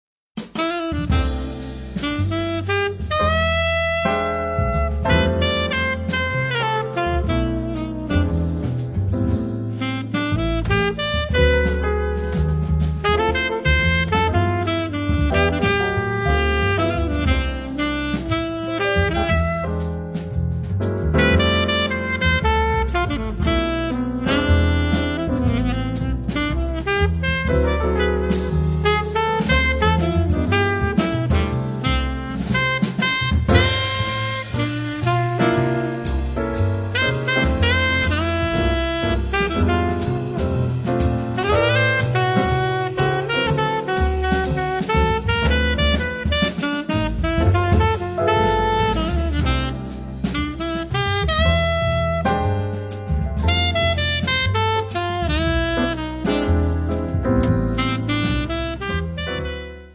sax contralto
pianoforte
contrabbasso
batteria